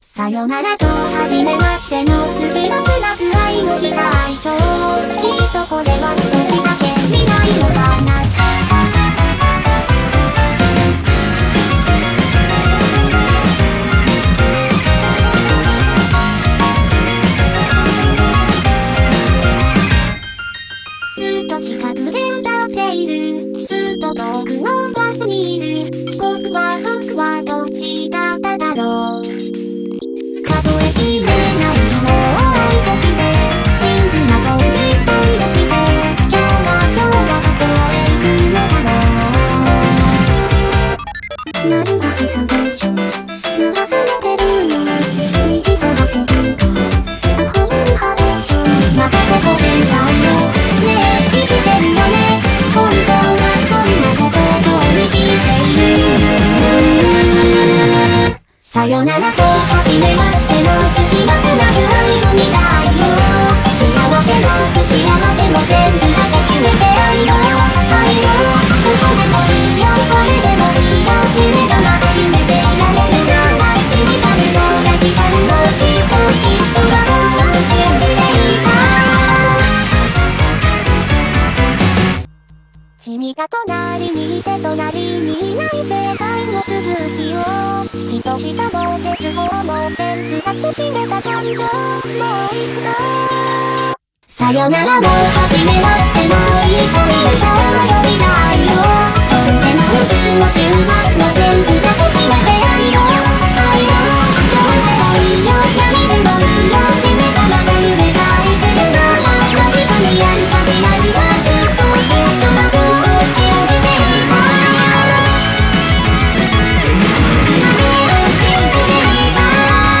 BPM190
Audio QualityPerfect (High Quality)
male backing vocals